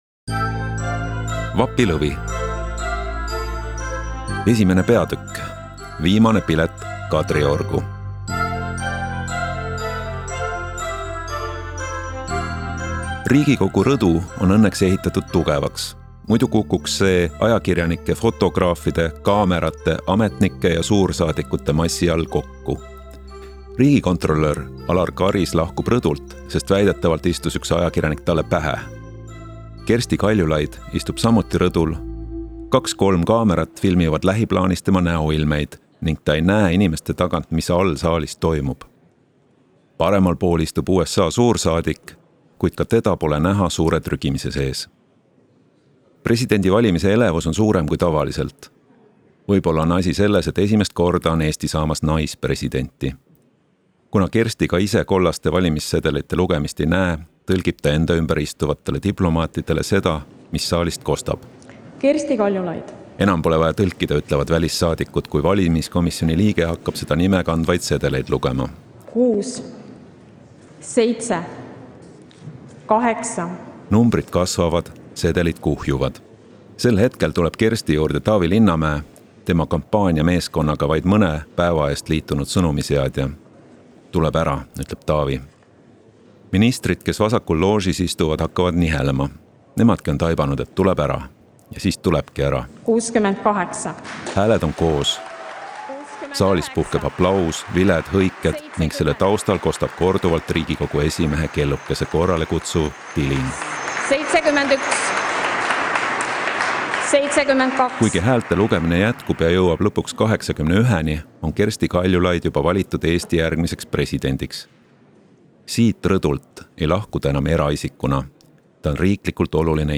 Vapilovi-I-osa-audiolugu.mp3